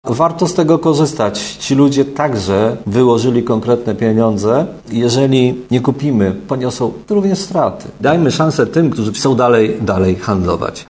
Mówi prezydent Tarnobrzega, Dariusz Bożek.